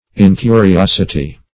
Incuriosity \In*cu`ri*os"i*ty\, n. [L. incuriositas: cf. F.